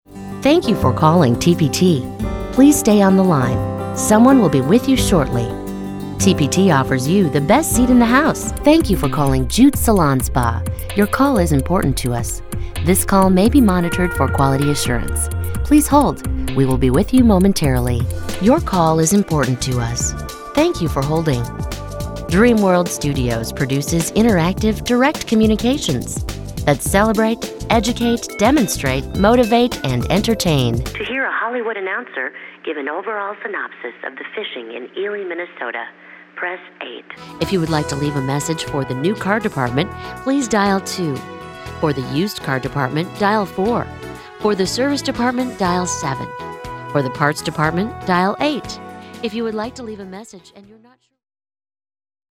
A smooth, warm and friendly voice that can instill trust and compassion as needed.
English - USA and Canada
Middle Aged